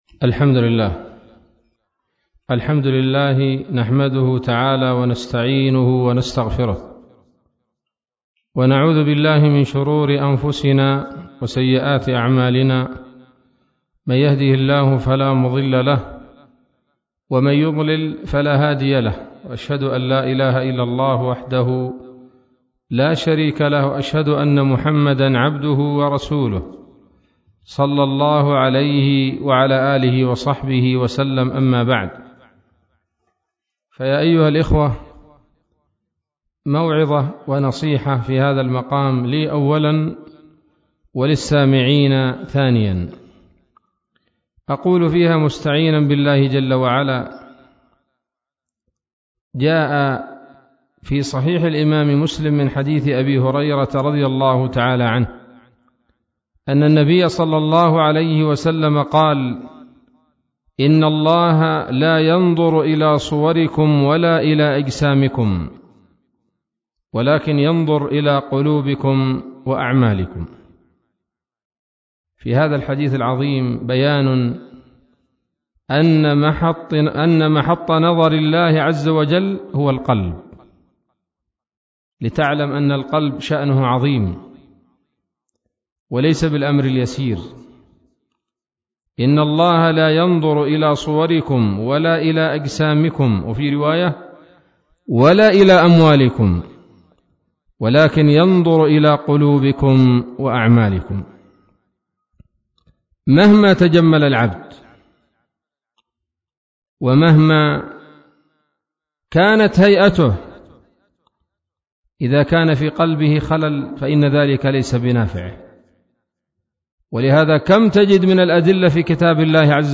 محاضرة بعنوان